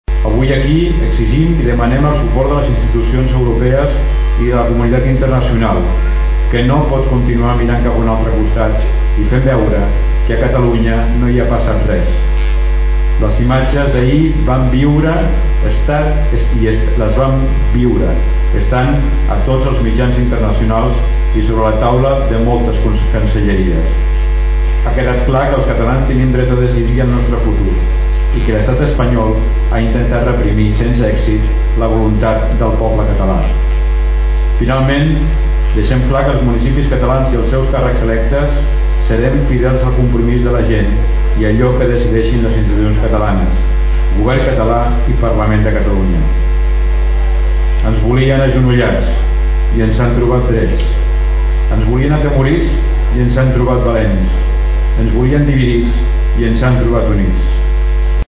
A la plaça de l’ajuntament, més d’un centenar de torderencs i torderenques s’hi han concentrat. L’alcalde de Tordera, Joan Carles Garcia ha estat l’encarregat de llegir un manifest redactat per l’associació de municipis per la independència i l’associació de municipis de Catalunya.
“Votarem” i “els carrers sempre seran nostres” són algunes de les proclames que s’han fet sentir a la concentració.